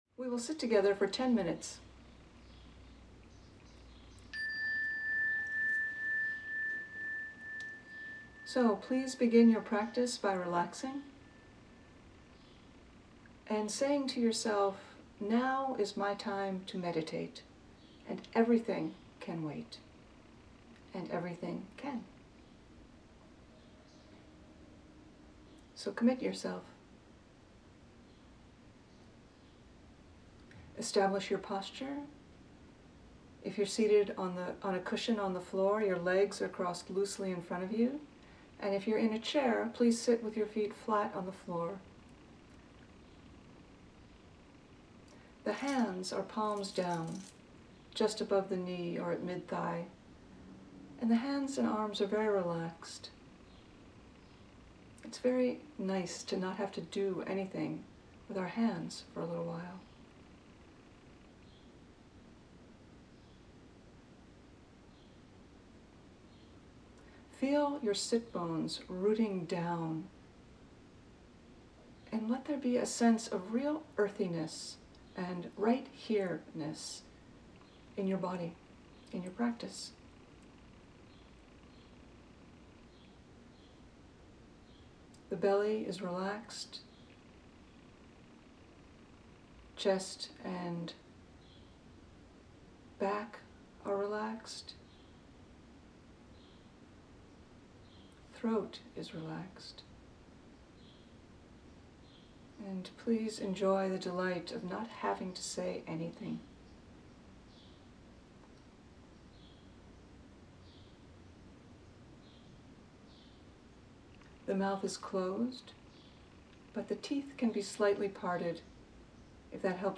Please click here for a recent guided 10-minute sit (audio only).
10-min_guided_audio.mp3